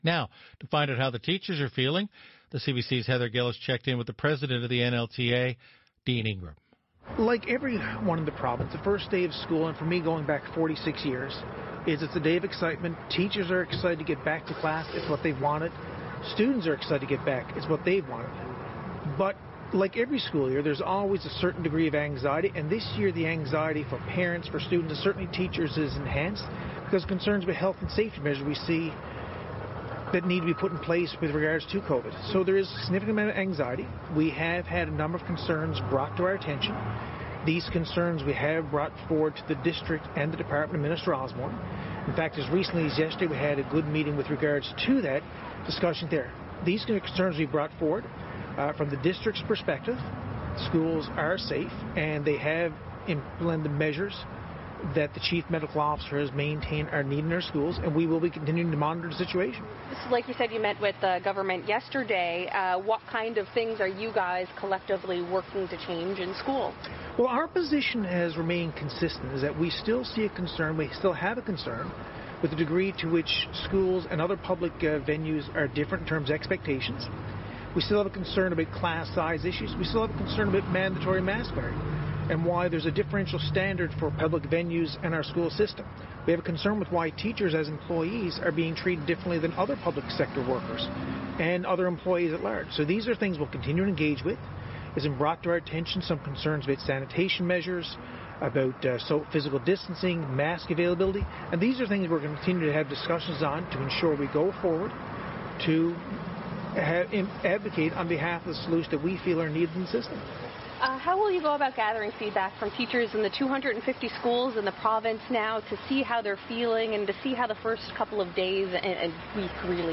Media Interview - CBC On the Go - Sept 9, 2020